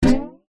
Звуки укулеле
Мелодия единственной струны укулеле